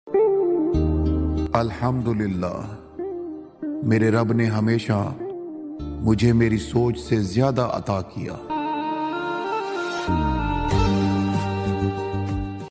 ⚠🔥Breaking Glass Bottles! 🌈 Crashing sound effects free download
Crunchy And Soft Things Bottles ASMR